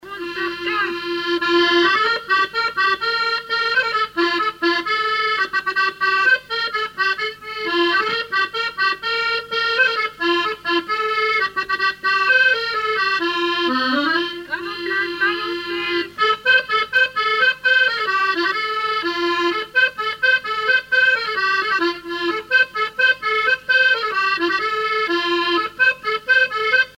danse : quadrille : avant-trois ;
Musique du quadrille local
Pièce musicale inédite